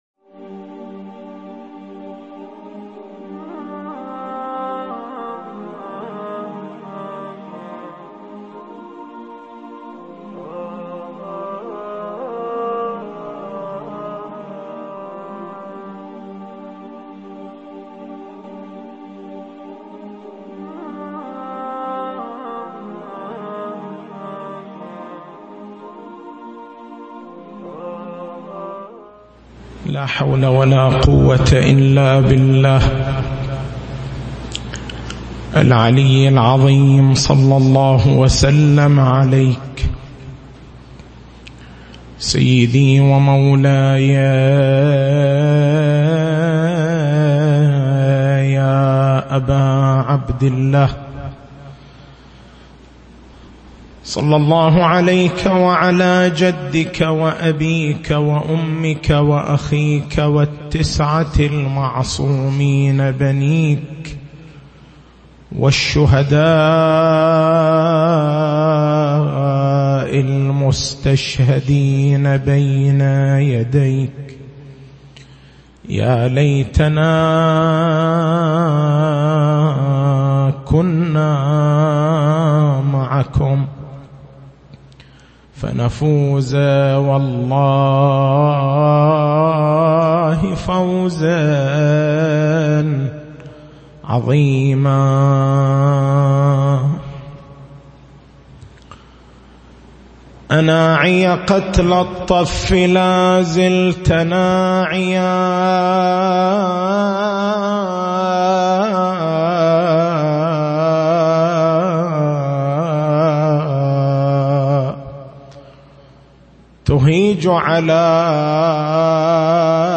تاريخ المحاضرة: 03/09/1439 محور البحث: بيان فلسفة اهتمام النصوص الدينية بمسألة معرفة الله سبحانه وتعالى من خلال أسمائه وصفاته.